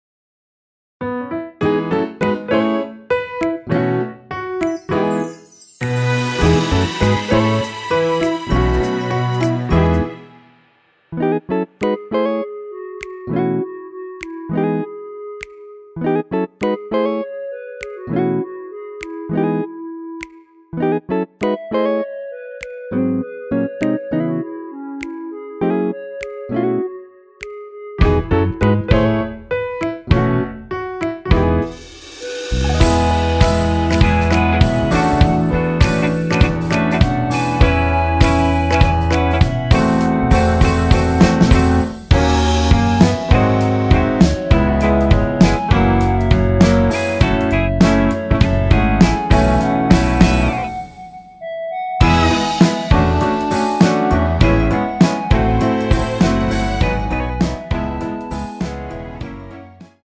원키에서 (+1)올린 멜로디 포함된 MR입니다.(미리듣기 참조)
노래방에서 노래를 부르실때 노래 부분에 가이드 멜로디가 따라 나와서
앞부분30초, 뒷부분30초씩 편집해서 올려 드리고 있습니다.